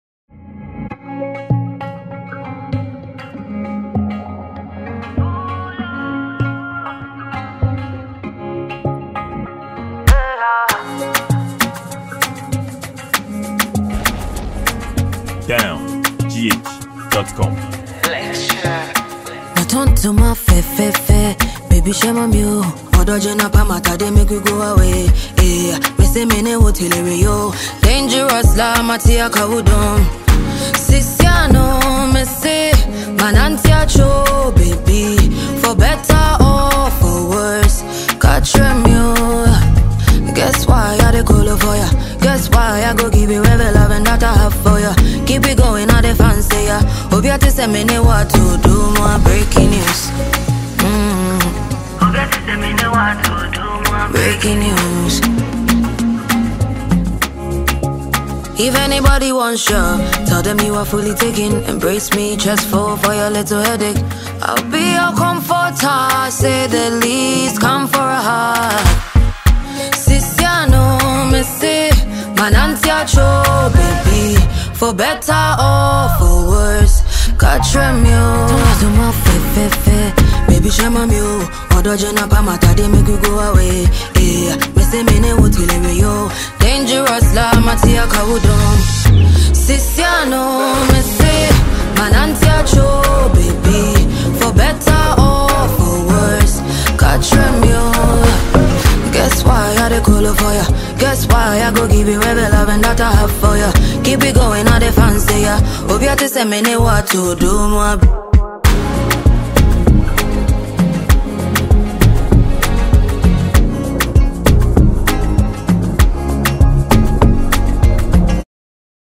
a highclass Ghanaian musician and singer
a free afrobeat Ghana song.